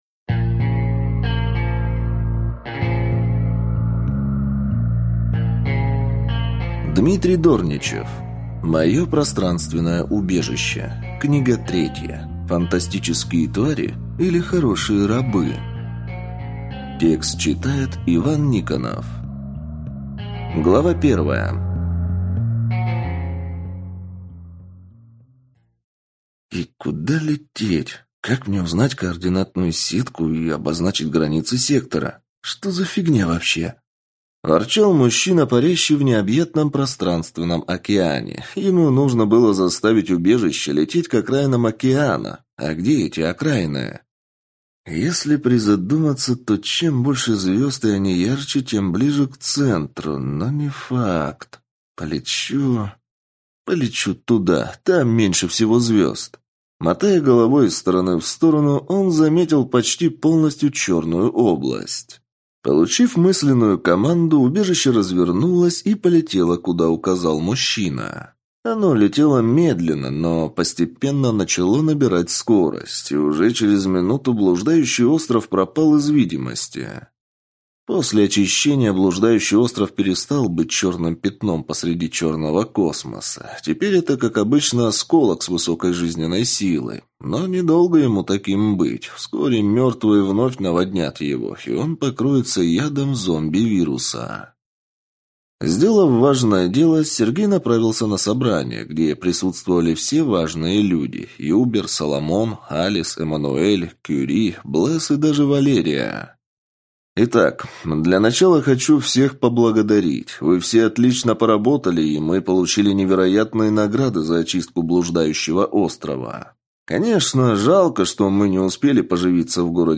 Аудиокнига Фантастические твари или хорошие рабы?